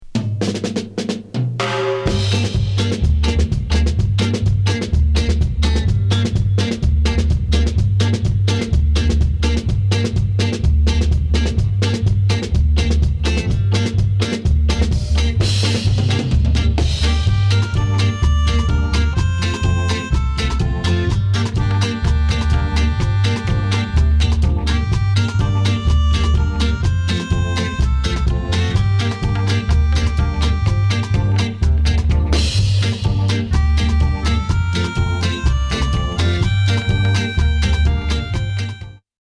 ドラムが正確で機械みたいにやたら上手いです。
タッタカタッタカと上品＆端正にしたマシンガン ドラミングにピアニアの演歌のような